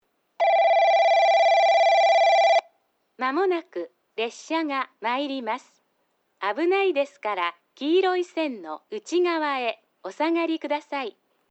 2番のりば接近放送　女声   放送はJR九州ホームが、九州カンノ型Cです。
スピーカーはJRホームがJVCラインアレイやカンノボックス型、おれんじ鉄道はTOAラッパ型です。